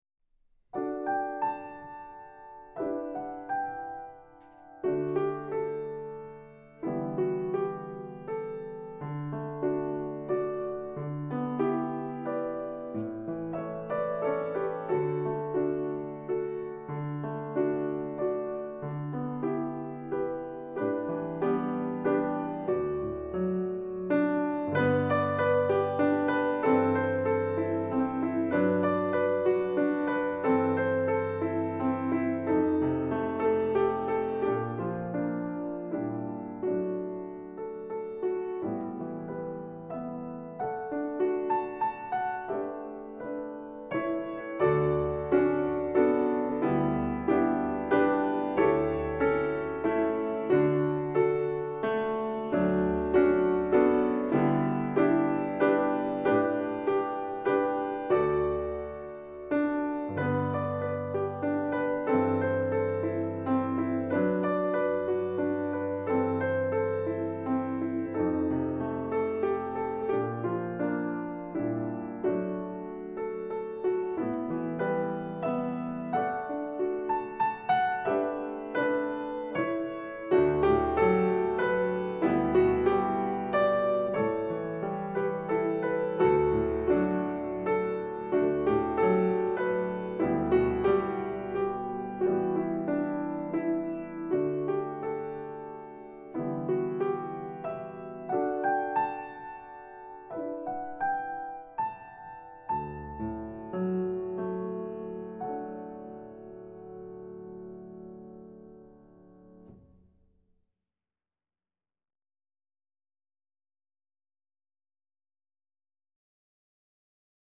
Accompaniment – High Voice